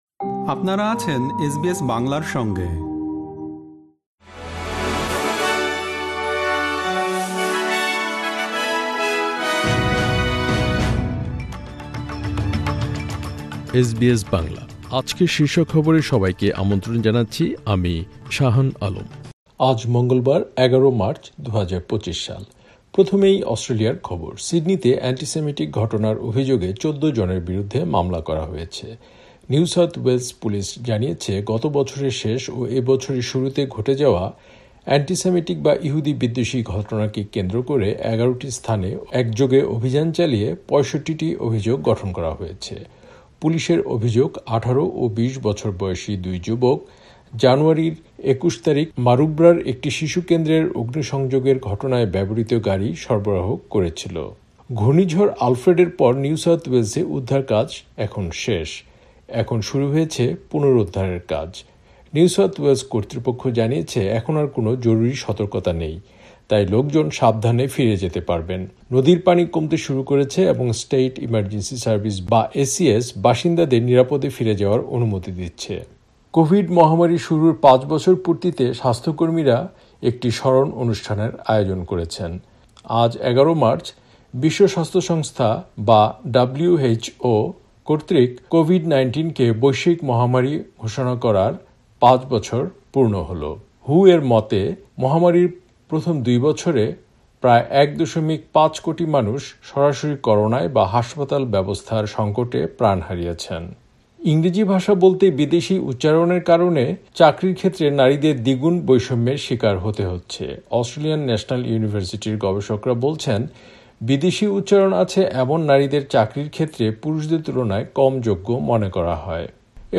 এসবিএস বাংলা শীর্ষ খবর: ১১ মার্চ, ২০২৫